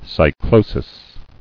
[cy·clo·sis]